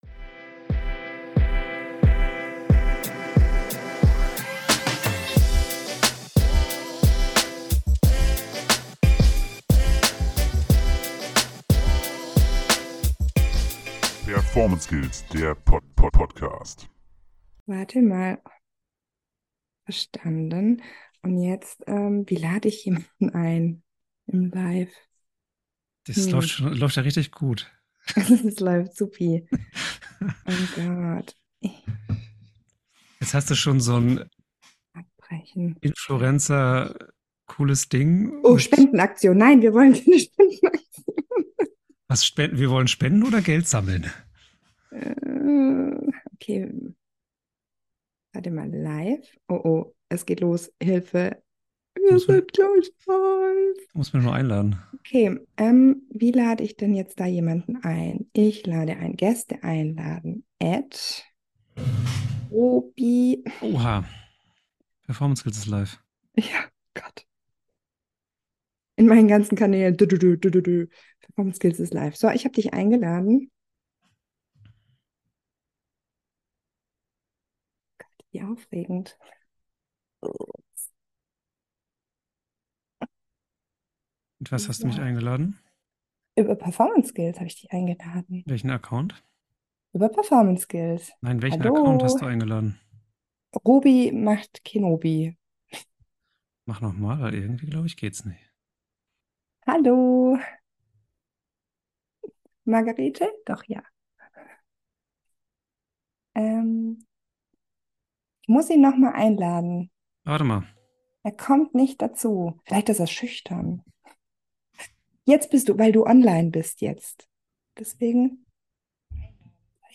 Beschreibung vor 2 Jahren In der neuen Folge sind wir tatsächlich das erste mal LIVE- auf Instagram gegangen und haben die Folge ungeschnitten hochgeladen! Ihr habt uns im Vorfeld viele Fragen zugeschickt und wir haben versucht diese zu beantworten.
Die Live-Folge haben wir nicht komplett geschnitten, sodass er flüssige gewohnte Ablauf natürlich nicht stattfindet :) Wir wünschen euch viel Spaß mit der neuen Folge !